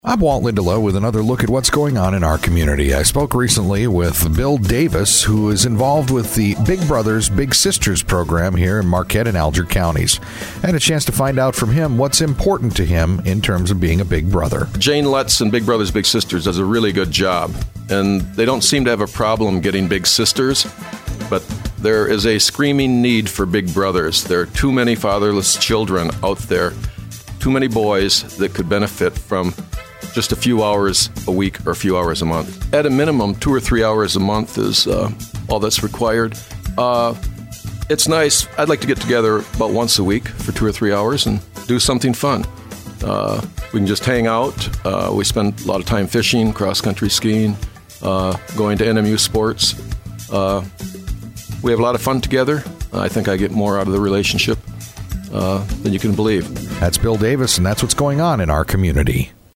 INTERVIEW: Big Brothers, Big Sisters of Marquette County